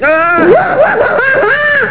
Pauly's laugh!
laugh.au